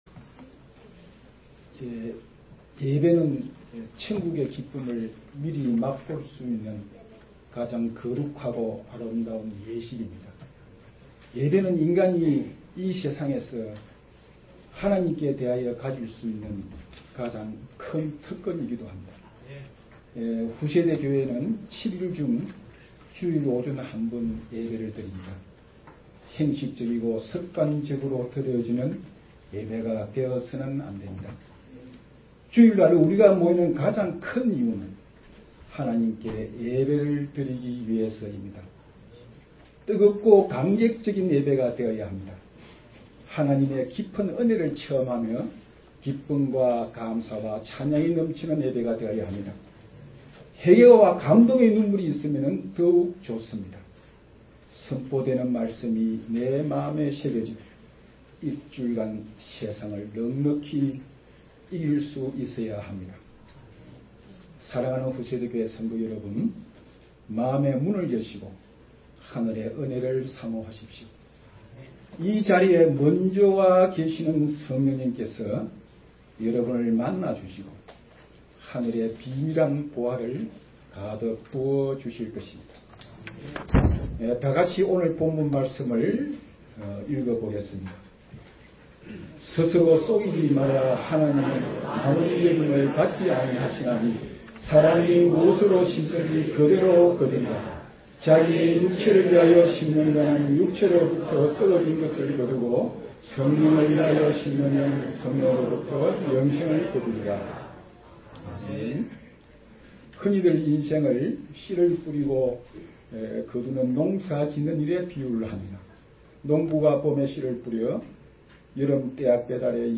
주일설교 - 09년 01월 18일 "심은대로 거두리라."